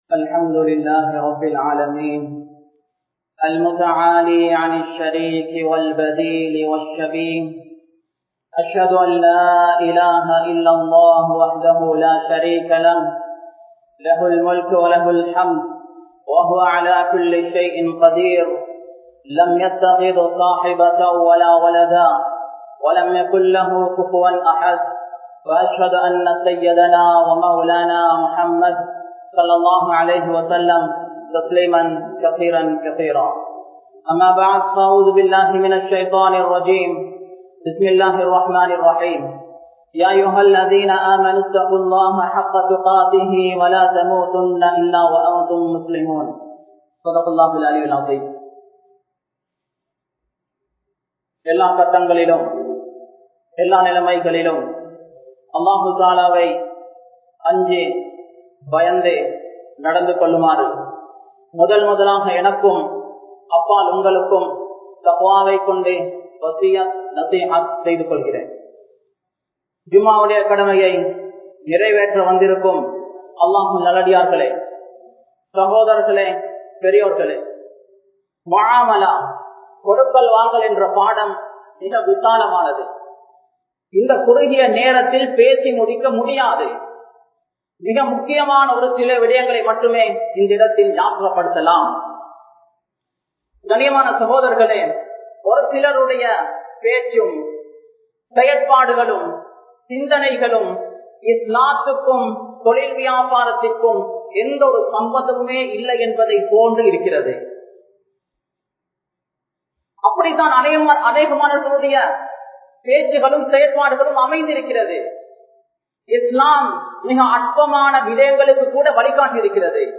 Islamiya Bank Murai (இஸ்லாமிய வங்கி முறை) | Audio Bayans | All Ceylon Muslim Youth Community | Addalaichenai